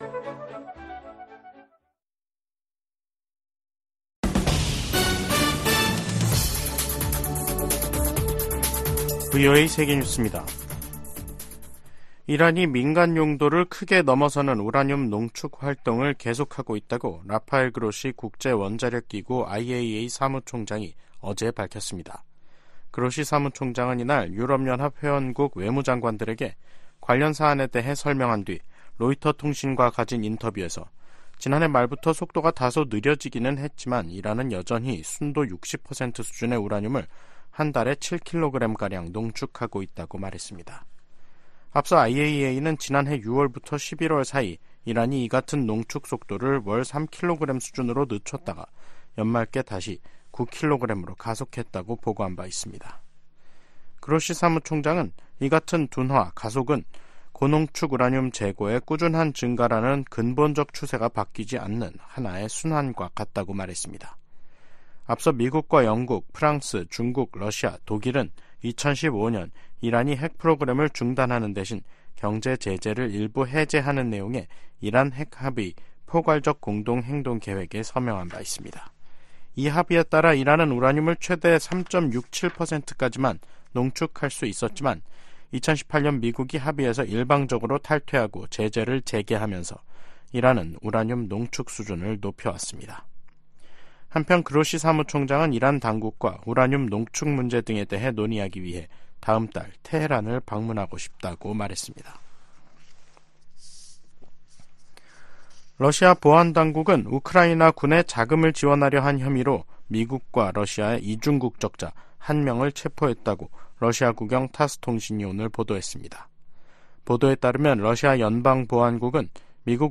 VOA 한국어 간판 뉴스 프로그램 '뉴스 투데이', 2024년 2월 20일 2부 방송입니다. 블라디미르 푸틴 러시아 대통령이 김정은 북한 국무위원장에게 러시아산 승용차를 선물했습니다. 미 국무부가 역내 긴장 고조는 미국 탓이라는 북한의 주장을 일축하고, 미국과 동맹의 연합훈련은 합법적이이라고 강조했습니다. 미 국무부는 유엔 북한인권조사위원회(COI) 최종 보고서 발표 10주년을 맞아 북한 정권에 인권 문제 해결을 촉구했습니다.